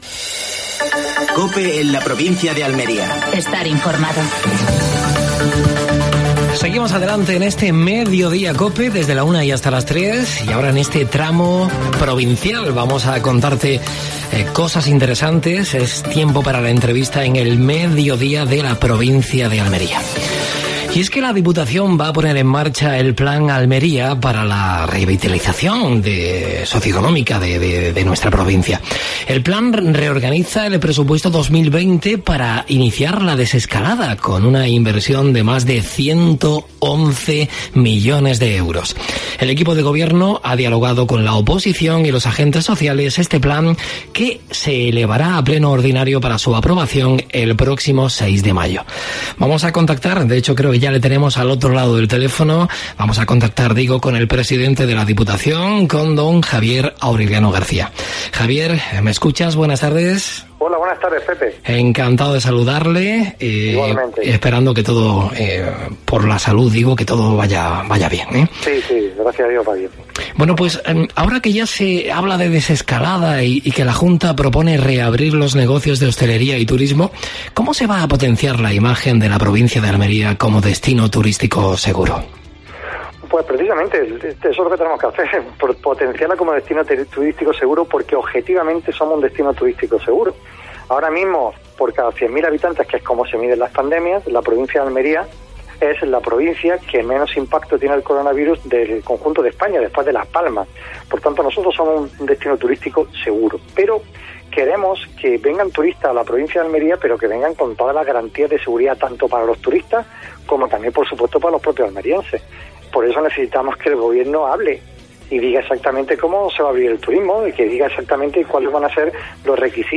AUDIO: Actualidad en Almería. Entrevista con Javier Aureliano García (presidente de la Diputación Provincial de Almería).